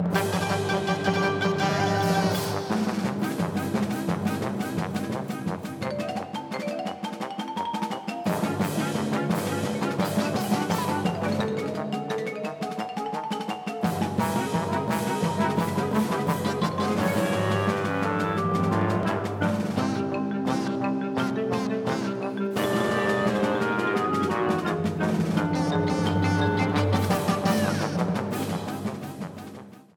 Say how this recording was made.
Ripped from game